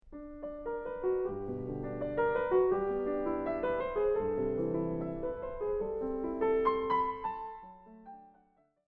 The first movement of the sonata Op. 14 No. 2 has an underlying movement of sixteenth-notes, when the right hand stops playing sixteen-notes, the left hand fills in so that the flow in not interrupted:
It is like a river with flowing water running in a steady, beautiful stream underneath.